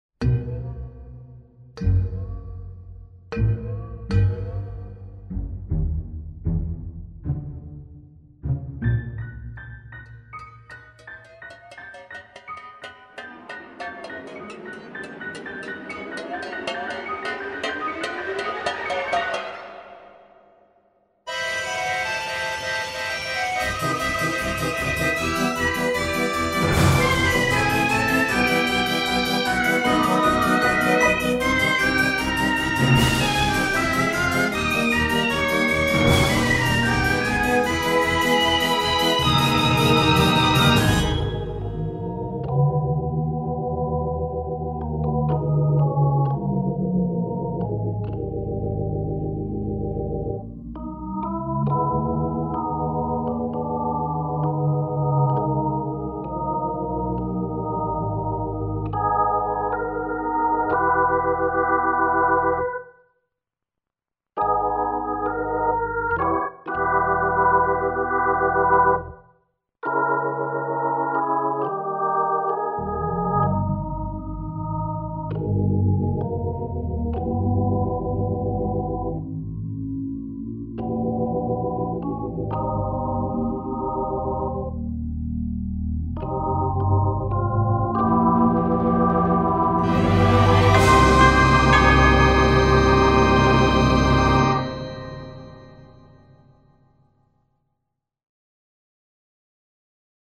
(Mystery / Comedy / Horror)